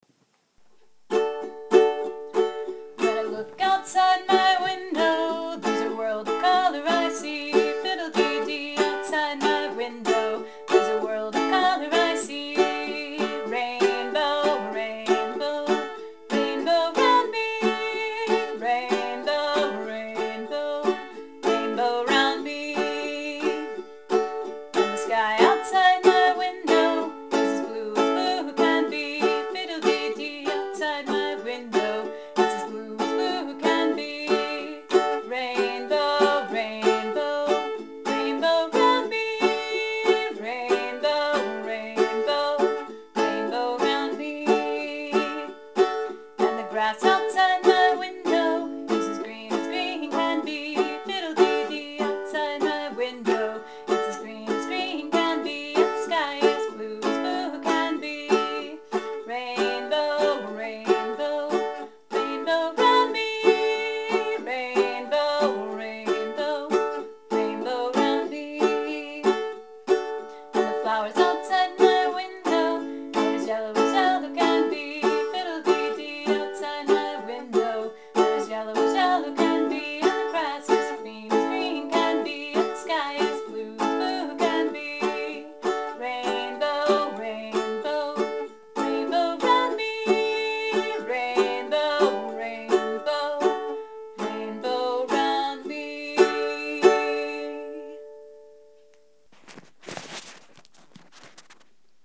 Posted in Rhymes and songs, Storytime themes, Ukulele by